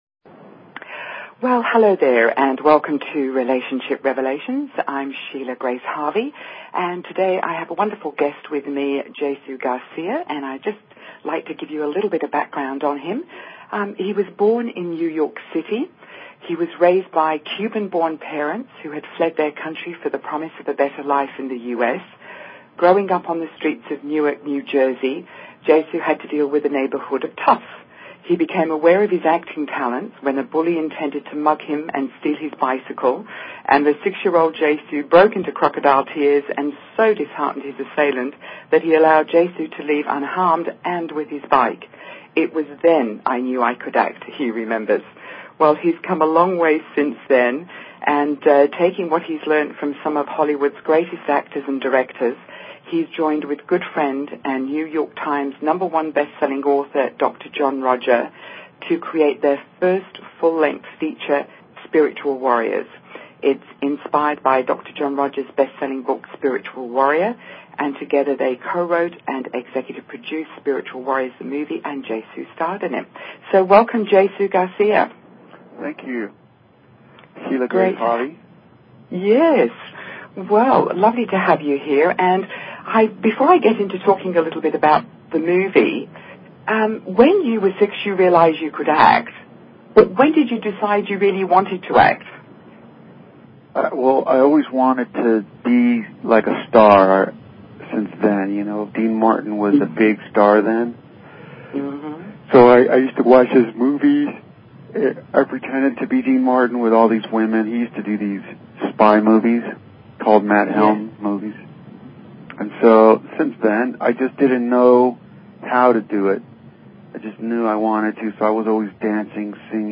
Talk Show Episode, Audio Podcast, Relationship_Revelations and Courtesy of BBS Radio on , show guests , about , categorized as